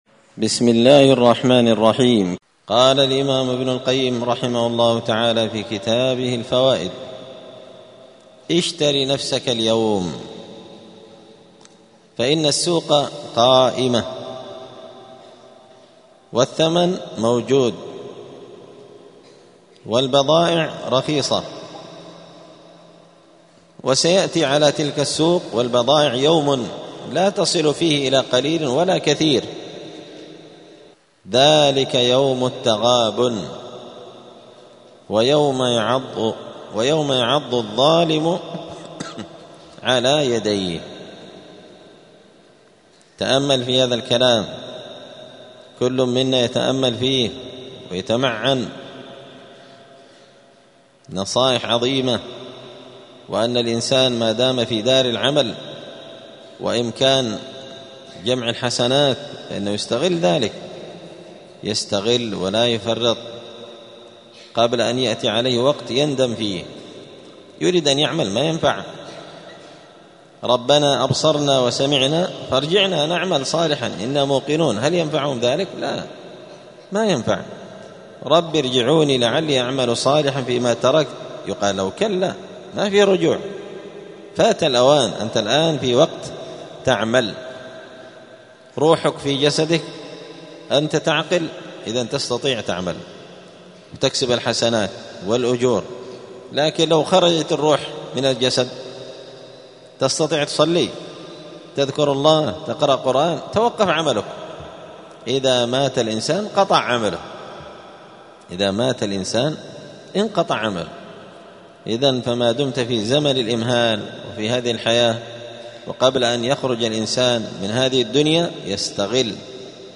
*الدرس الثاني والعشرون (22) (فصل: اشتر نفسك اليوم قبل يوم التغابن)*